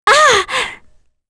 Kirze-Vox_Attack2.wav